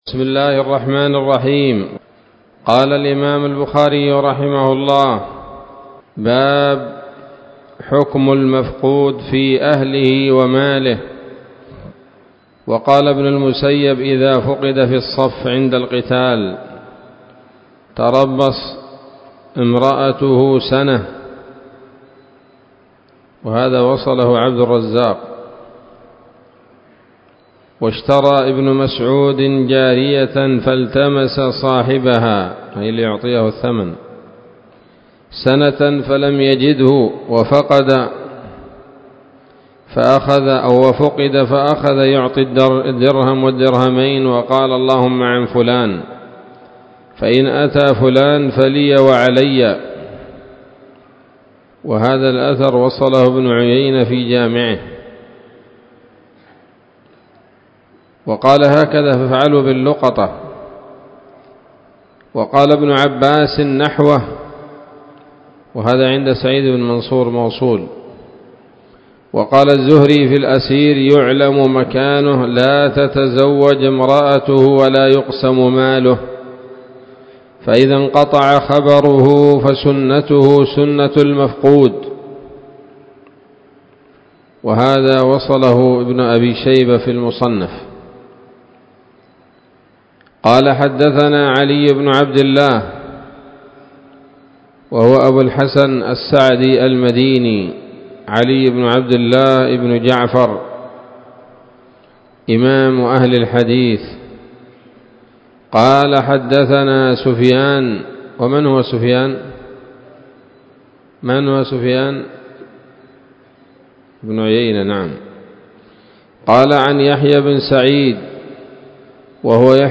الدرس السابع عشر من كتاب الطلاق من صحيح الإمام البخاري